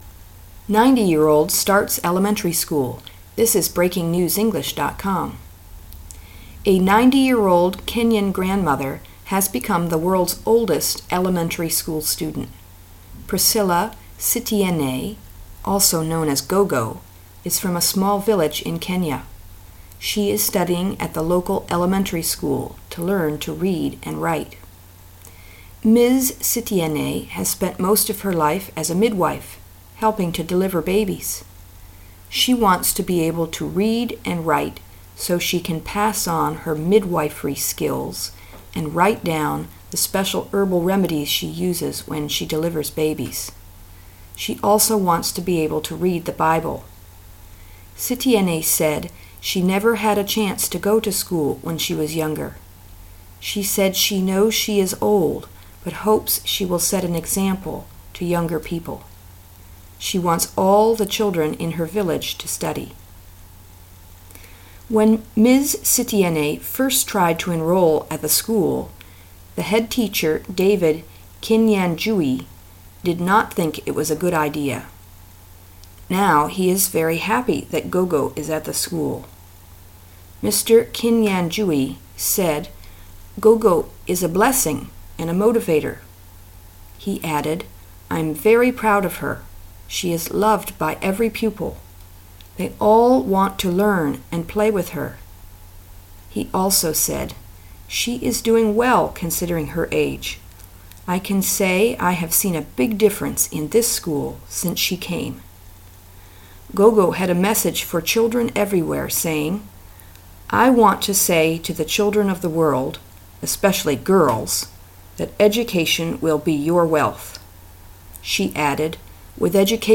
British speaker (slower speech)